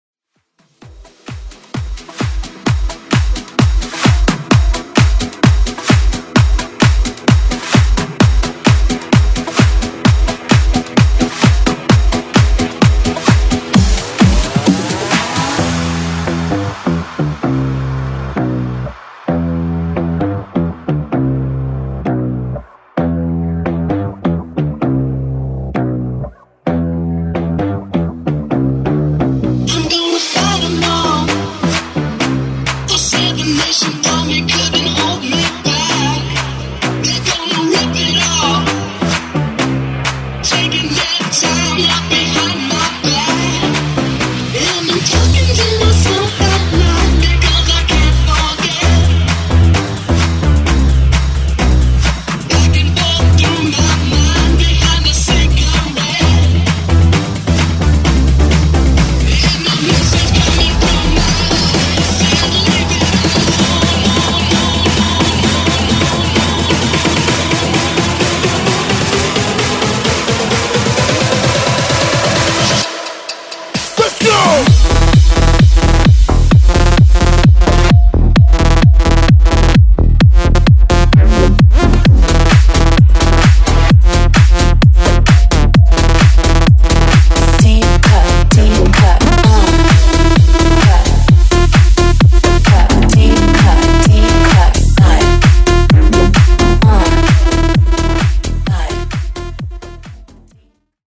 Genre: FUTURE HOUSE Version: Clean BPM: 126 Time